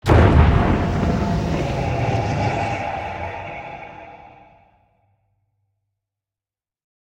endportal.ogg